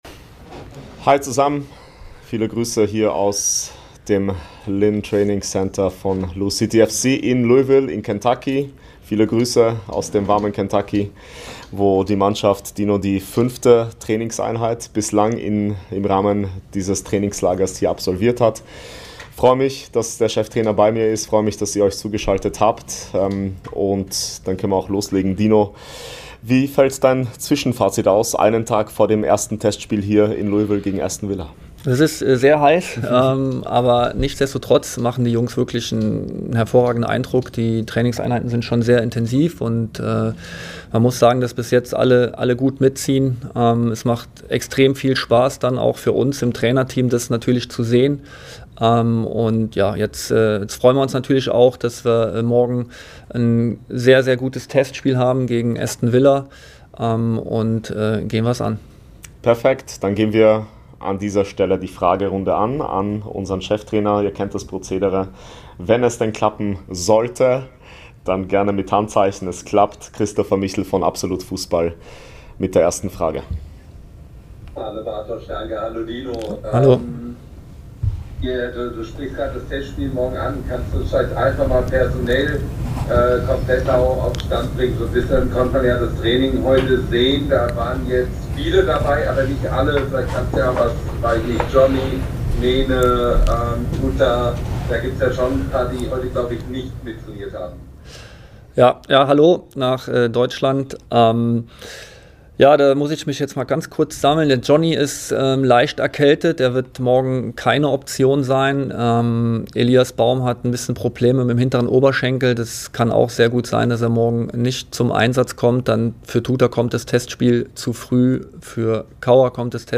Cheftrainer Dino Toppmöller spricht an Tag vier der ADIDAS U.S. TOUR in Louisville unter anderem über das Testspiel gegen Aston Villa, die bisherigen Trainingseindrücke, die Personalsituation und seine Erwartungen an das Match am Samstag.